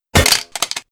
Weapon_Drop 02.wav